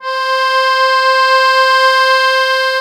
MUSETTESW.10.wav